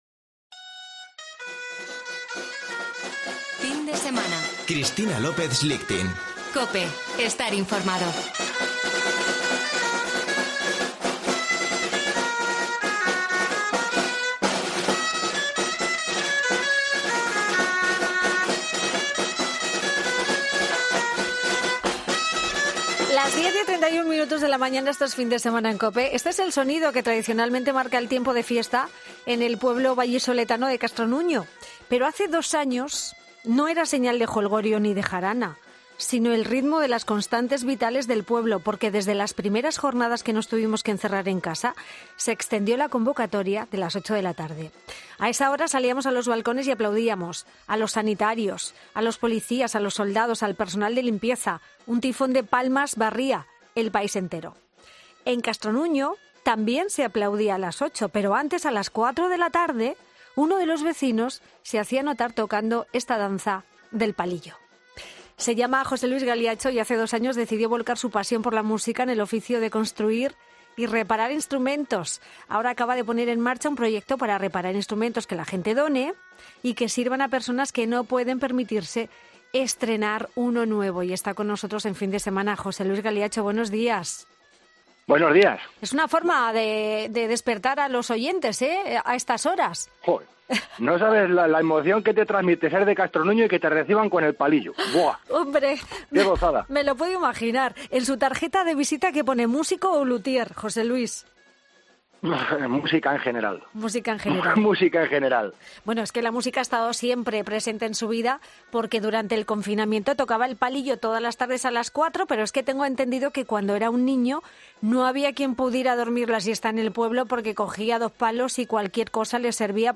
charla con el lutier vallisoletano